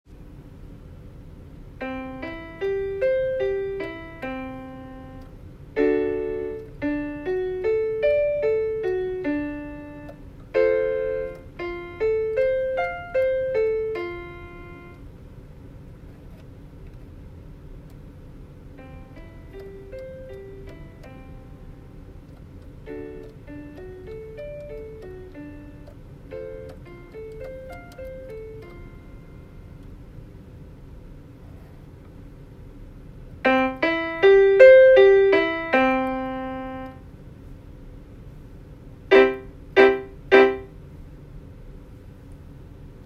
音源の再生環境に大きく影響されるため、あくまで参考なのですが、SA-51の音を録音してみました。
デスクにSA-51を置き、iPhoneを耳元にかざしながらボイスメモアプリを使い録音しました。スピーカーの位置とiPhoneのマイクの距離は約40～50cmくらいです。
音量注意！
00:15までは音量調整ツマミが真ん中の状態での音量、その後00:18からは最小での音量、00:33からは最大の音量です。
最大まで上げると近距離だとめちゃくちゃ五月蝿いくらいの音量になります。上の音源でも五月蝿すぎるので、おっかなびっくり鍵盤を押しています笑
1. 安っぽいピアノの音質